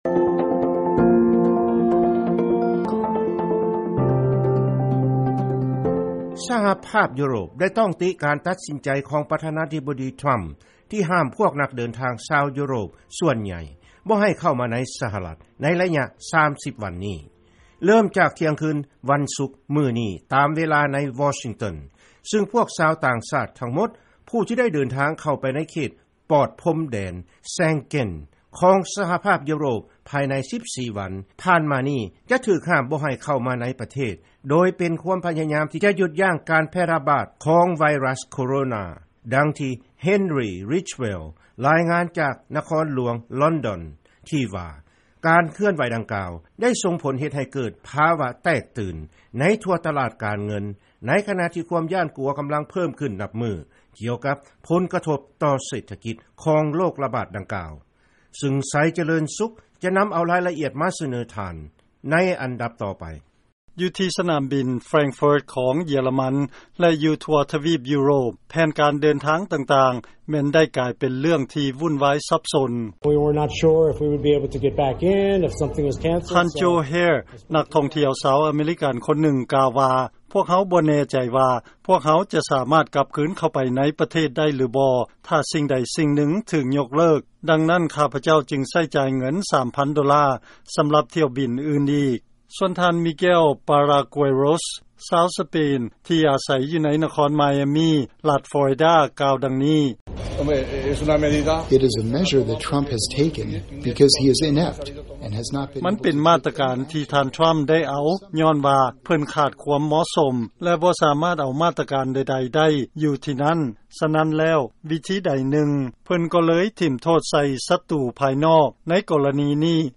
ເຊີນຟັງລາຍງານ ຢູໂຣບ ກ່າວໂຈມຕີ ການຫ້າມເດີນທາງຂອງ ສຫລ ຂະນະທີ່ ຕະຫຼາດການເງິນທັງຫຼາຍ ຕົກລົງຢ່າງໜັກໜ່ວງ